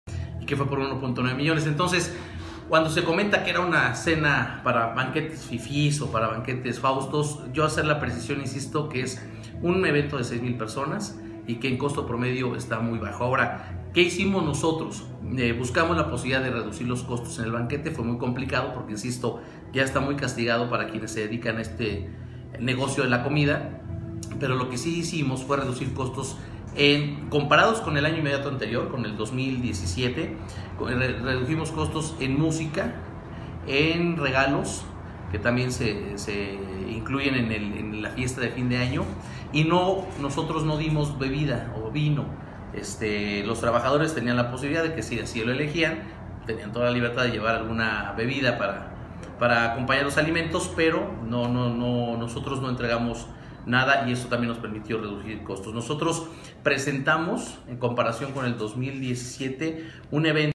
En entrevista con Efekto 10 Noticias, el funcionario municipal precisó que el costo del platillo que se ofreció en la cena de navidad por persona fue de 238 pesos, el cual detalló que fue “muy austero” de 4 tiempos donde incluyó servicio de mantelería, de mesas, de sillas, meseros, de cocineros y en general todo el servicio que se pagó, el cual se eligió entre tres personas que presentaron el presupuesto.